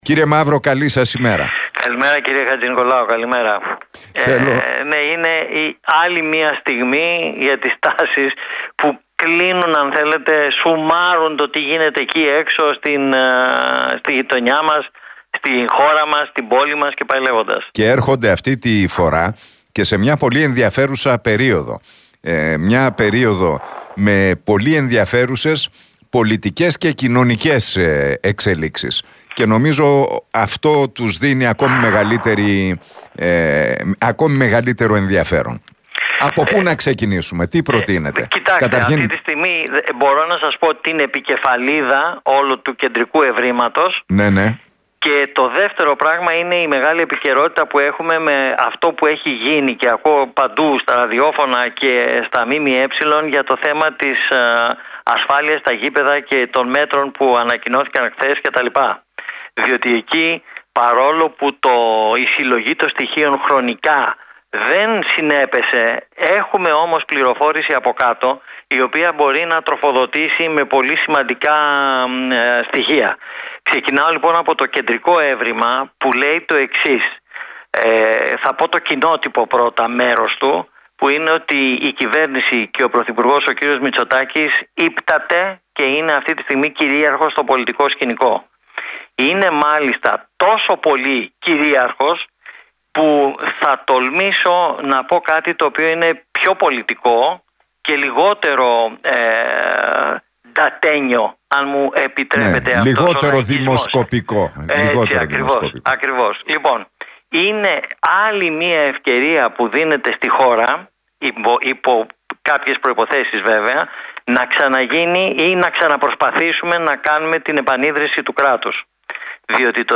μιλώντας στον Realfm 97,8 και την εκπομπή του Νίκου Χατζηνικολάου.